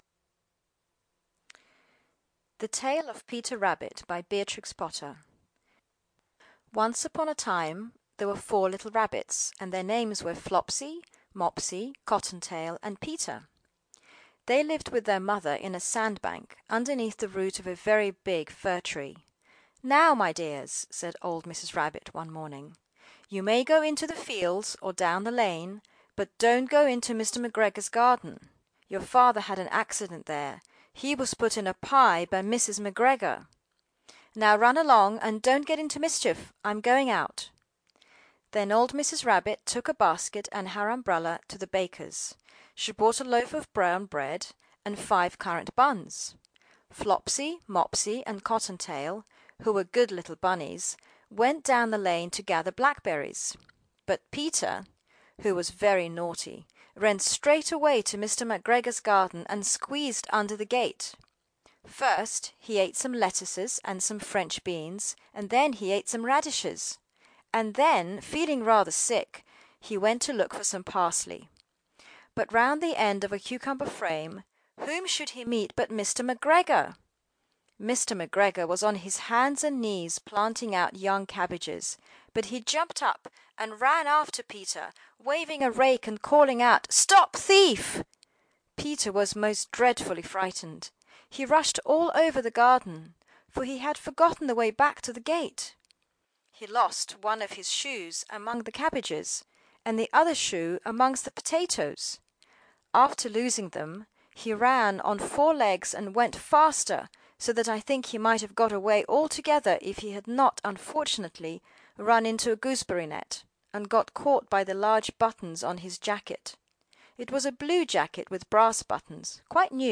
Peter Rabbit by Beatrix Potter ~ Full Audiobook [children]